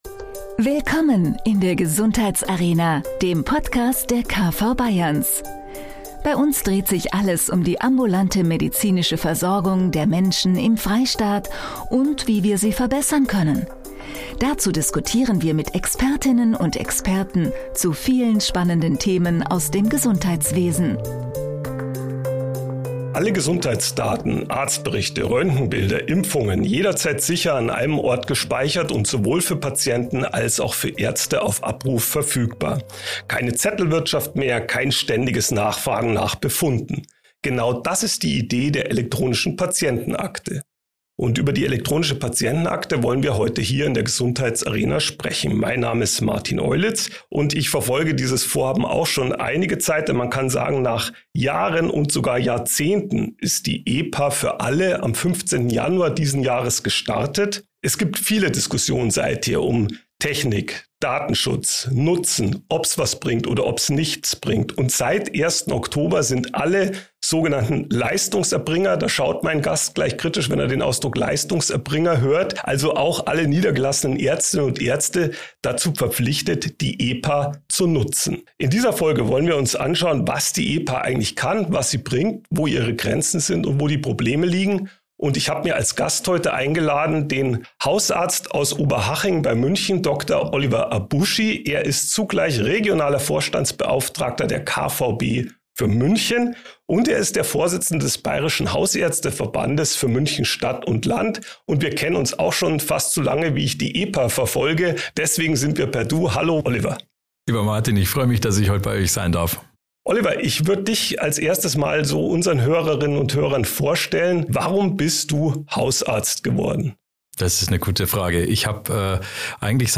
In einer Straßenumfrage beleuchten wir die aktuelle Stimmungslage der Patientinnen und Patienten.